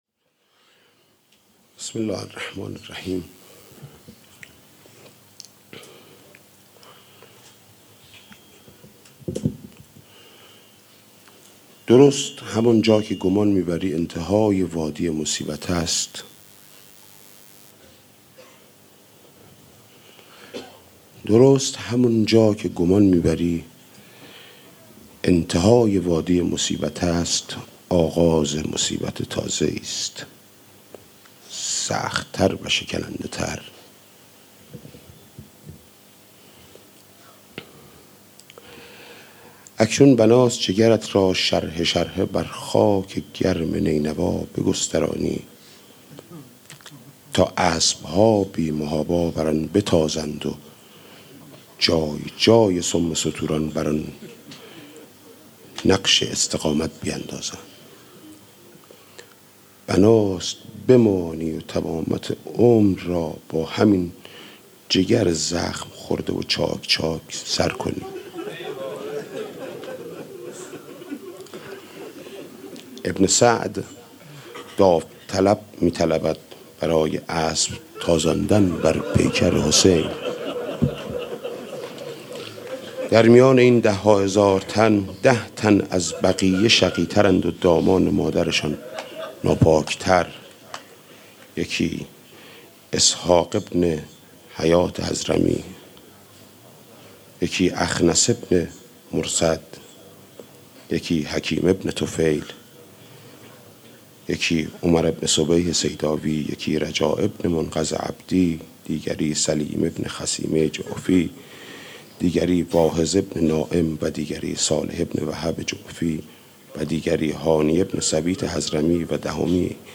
قرائت قسمتی ار کتاب آفتاب در حجاب